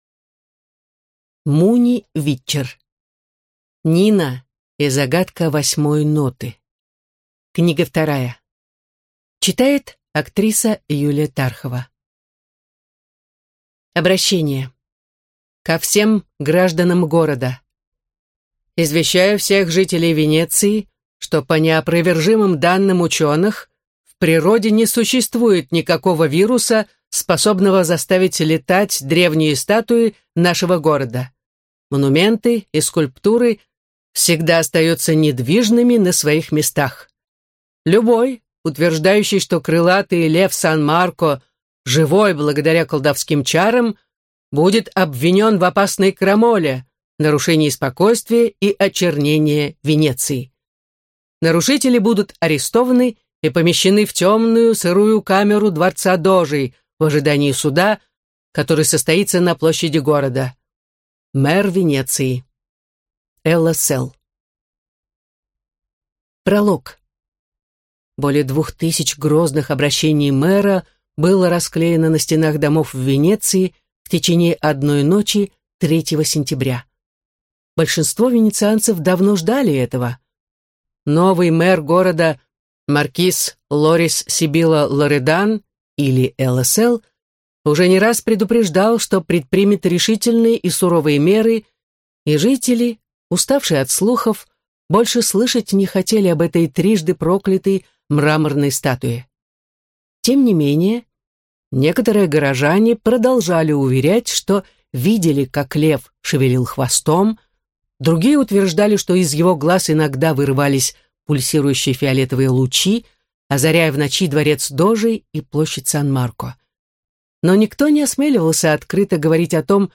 Аудиокнига Нина и загадка Восьмой Ноты | Библиотека аудиокниг
Прослушать и бесплатно скачать фрагмент аудиокниги